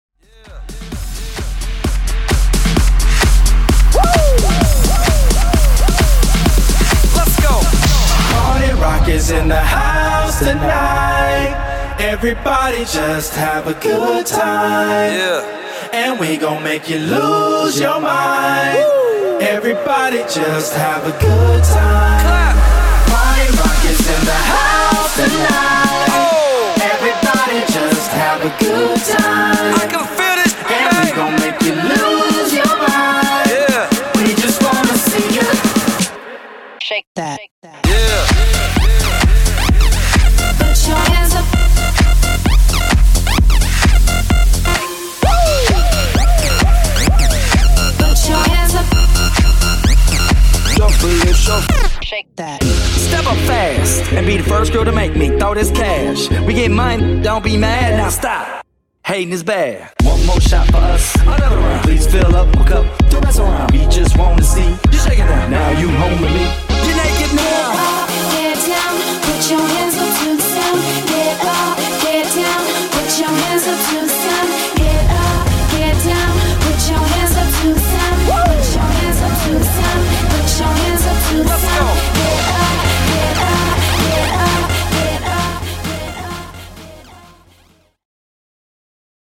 Genre: DANCE Version: Clean BPM: 130 Time